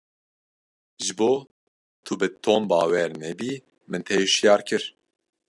Pronounced as (IPA) /nɛˈbiː/